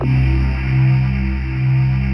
Index of /90_sSampleCDs/USB Soundscan vol.28 - Choir Acoustic & Synth [AKAI] 1CD/Partition D/17-GYRVOC 3D